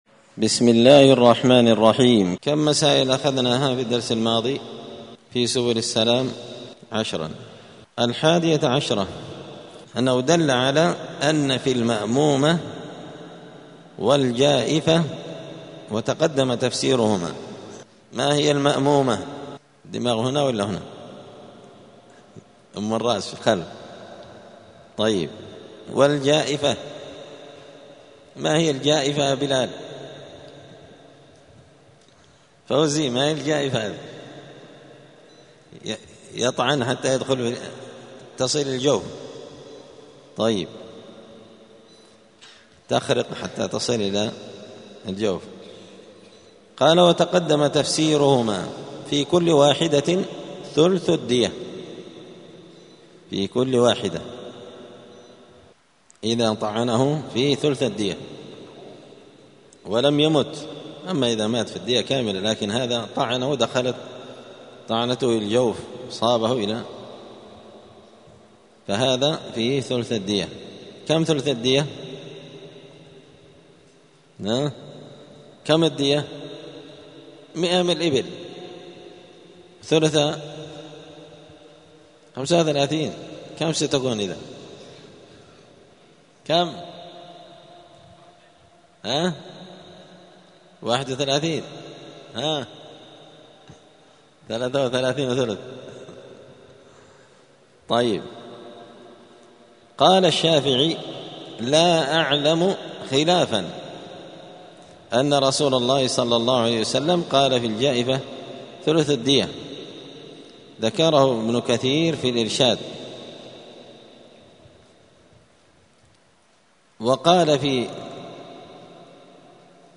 *الدرس العشرون (20) {باب الديات دية المأمومة والجائفة}*